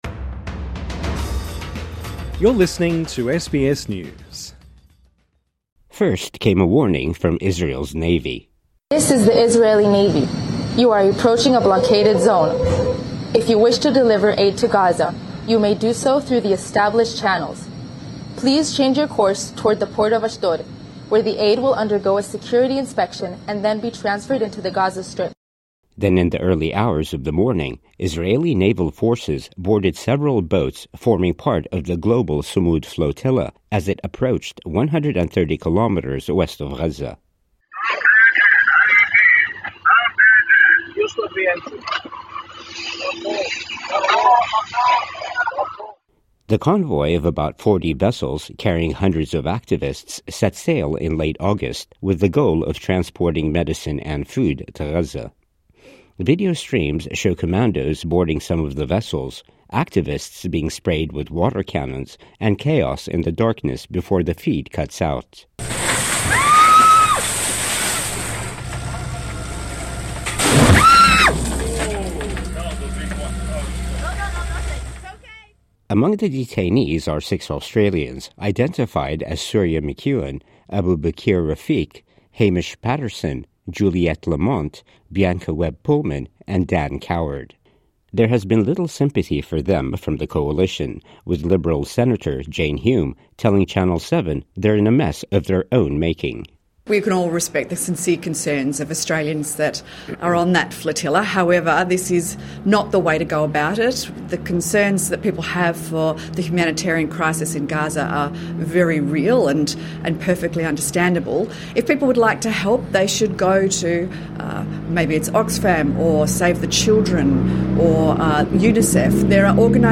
(The sound of officers announcing they are coming on board) The convoy of about 40 vessels - carrying hundreds of activists - set sail in late August, with the goal of transporting medicine and food to Gaza.